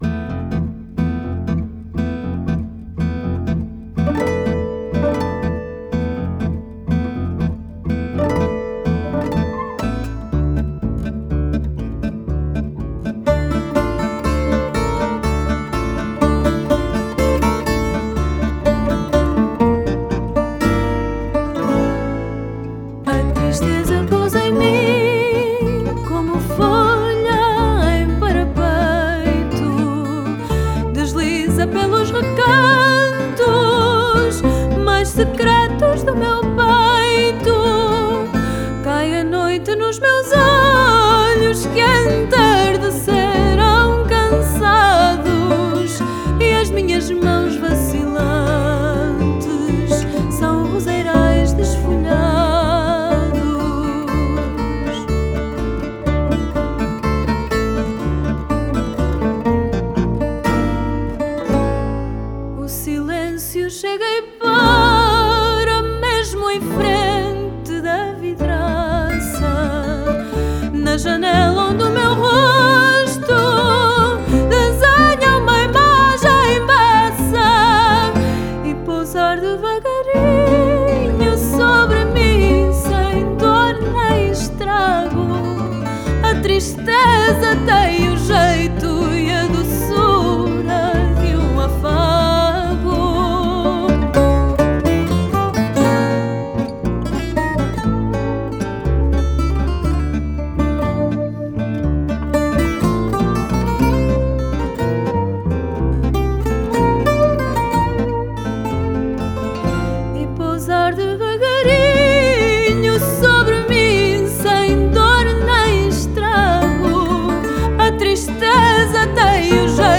Style: Fado